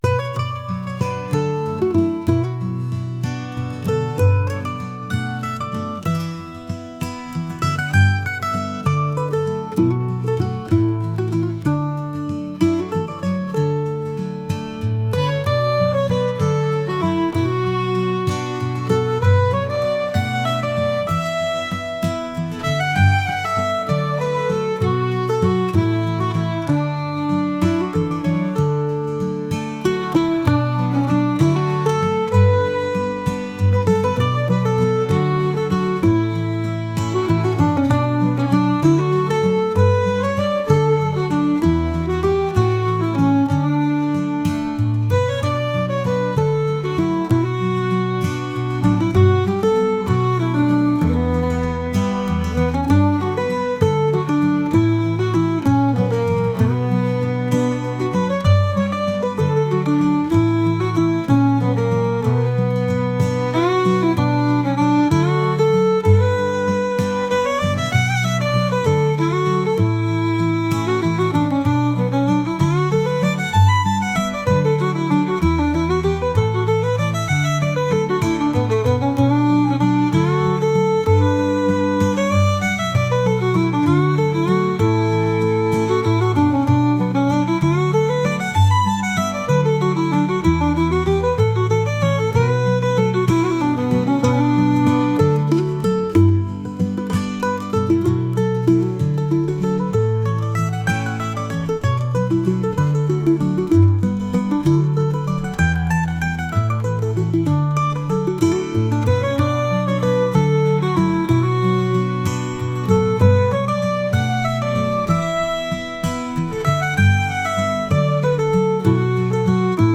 朽ちていく歴史を感じながらまた再生に向かって生きるケルト音楽です。